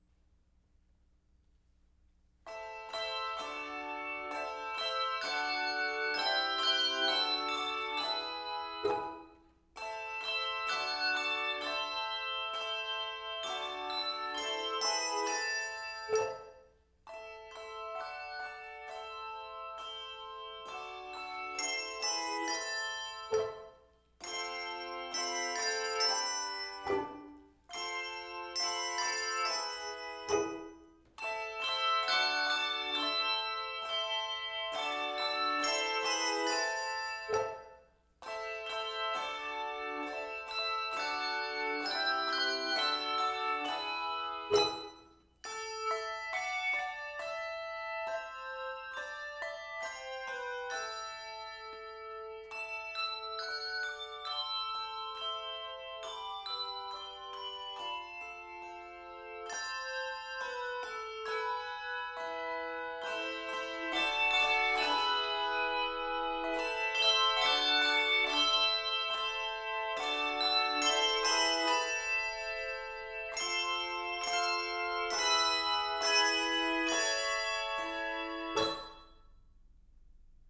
Voicing: Handbells 2-3 Octave